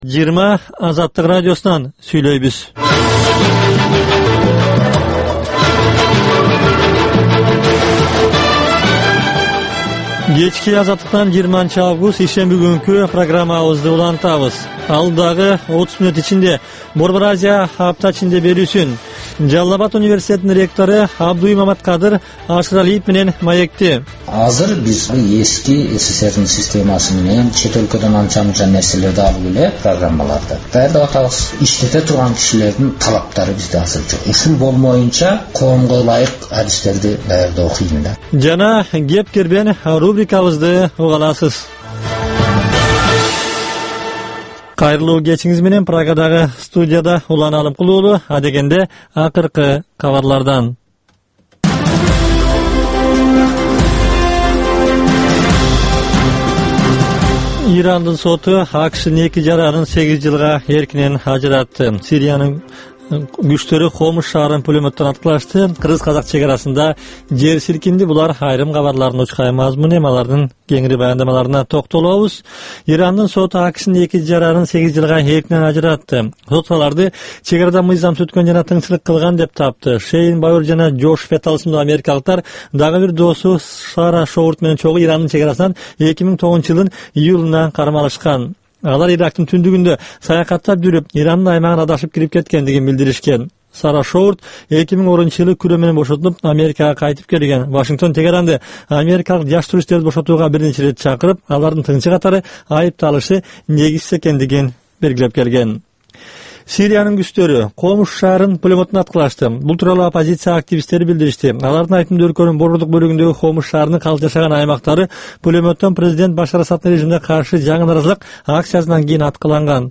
Кечки 8деги кабарлар